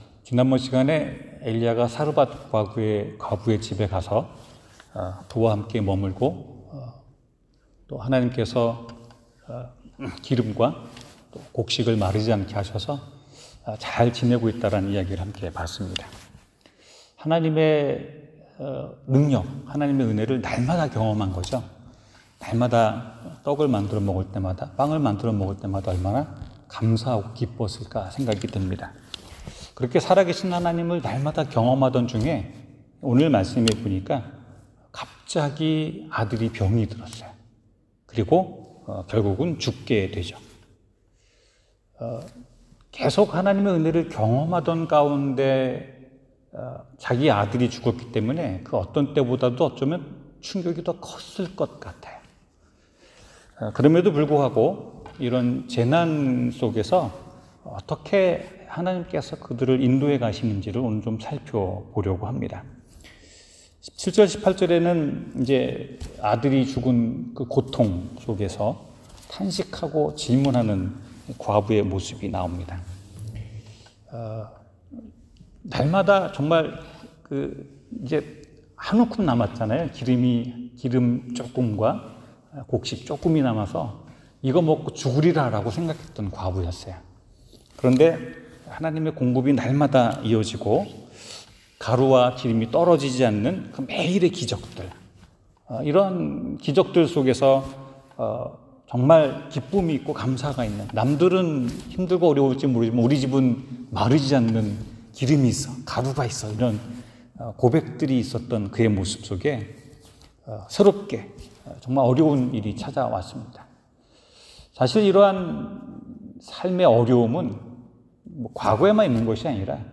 하나님이 들으십니다 성경 : 열왕기상 17:17-24 설교